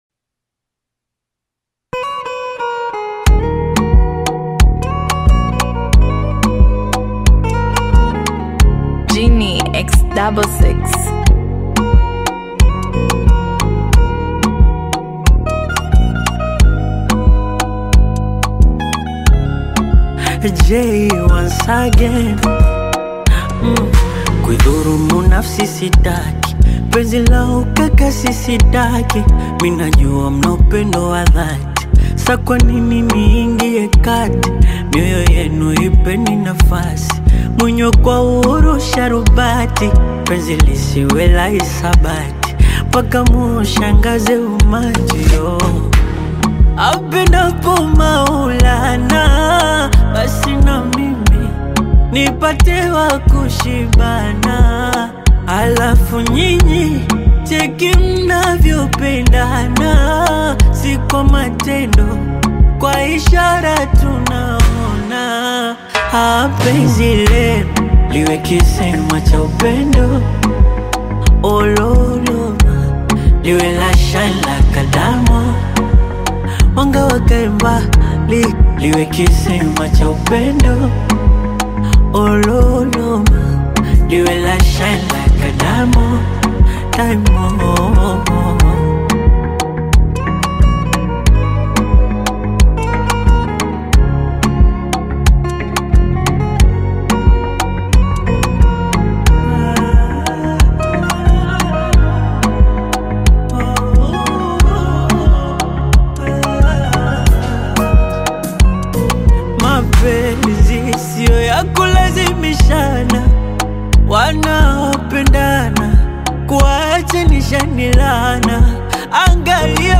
African rhythms, soulful melodies, and modern pop influences